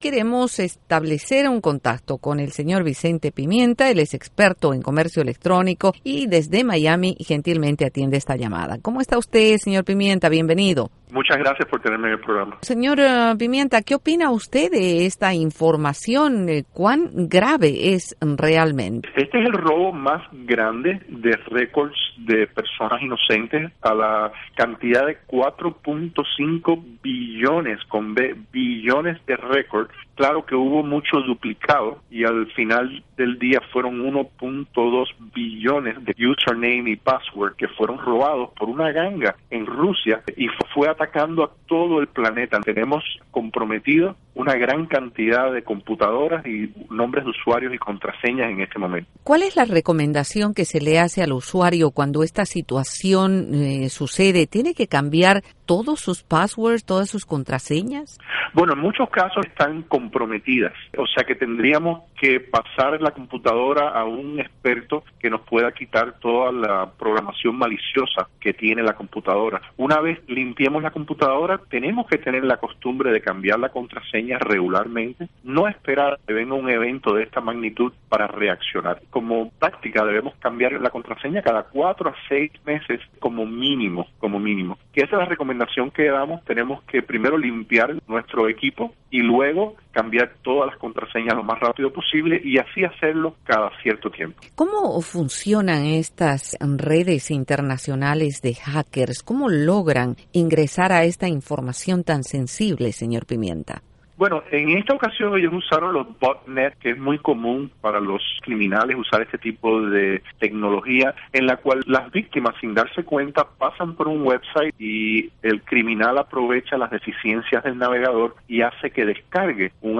Emtrevista